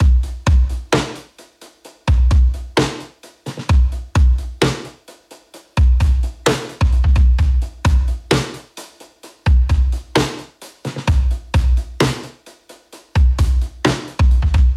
80s Touch（DB-30 OFF / ON）
80s-Touch-WET[660].mp3